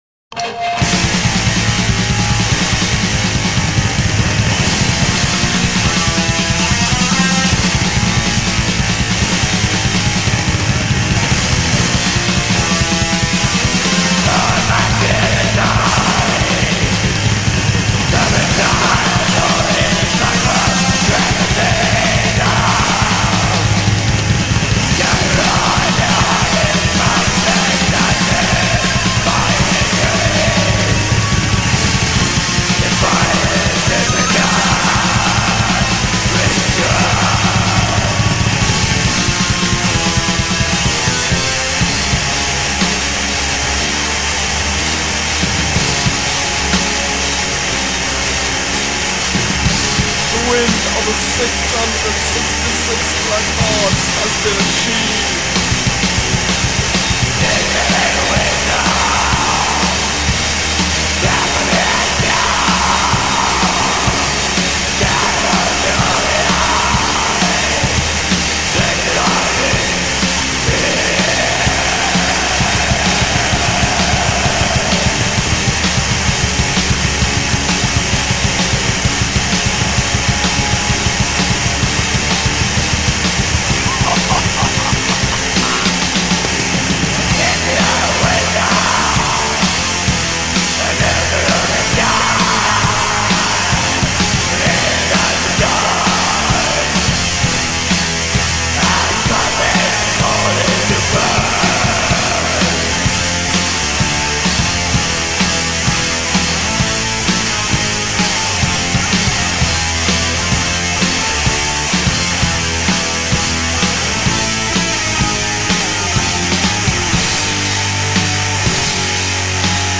> اثری از یکی از مهم ترین بند های بلک متال البوم
black metal